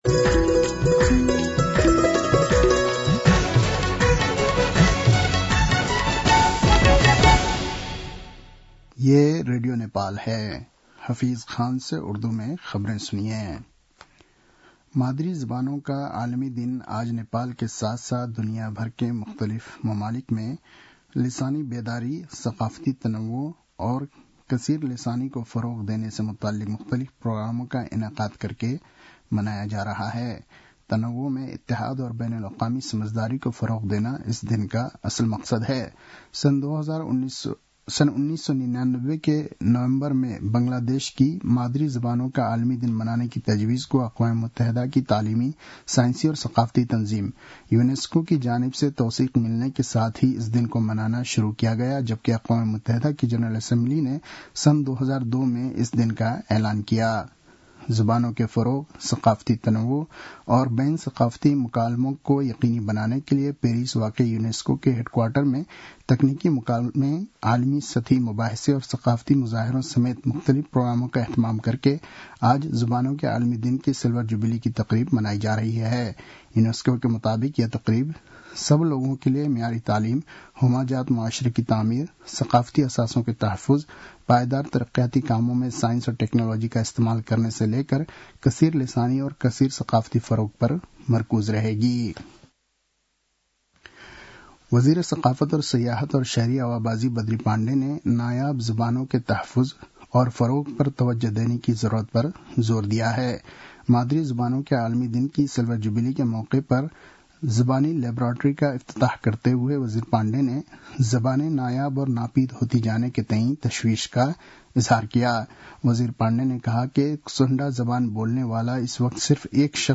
उर्दु भाषामा समाचार : १० फागुन , २०८१